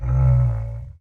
Minecraft Version Minecraft Version snapshot Latest Release | Latest Snapshot snapshot / assets / minecraft / sounds / mob / camel / ambient7.ogg Compare With Compare With Latest Release | Latest Snapshot